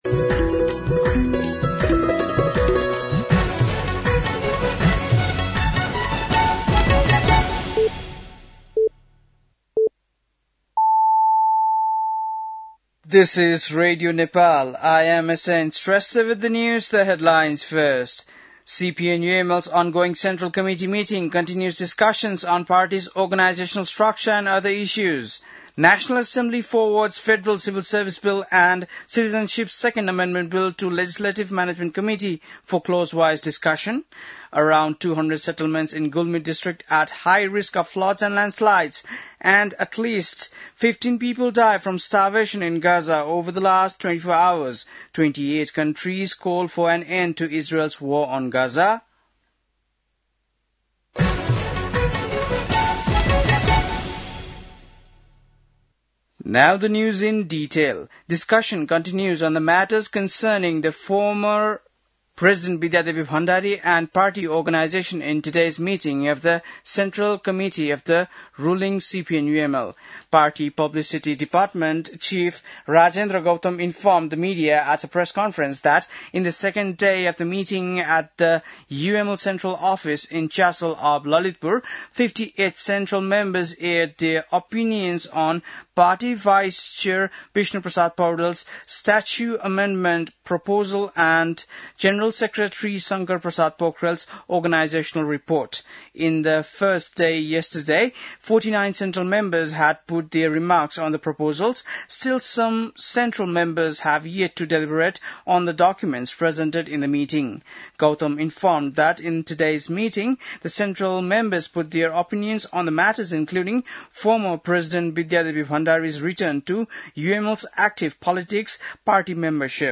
बेलुकी ८ बजेको अङ्ग्रेजी समाचार : ६ साउन , २०८२